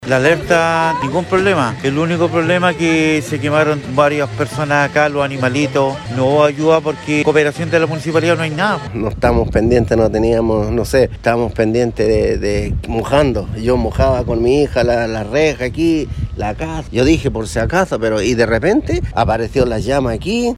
Así lo dieron a conocer a Radio Bío Bío algunos de los vecinos afectados por la emergencia en el sector Pompeya Sur en Quilpué.